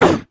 Index of /App/sound/monster/ice_snow_witch
damage_1.wav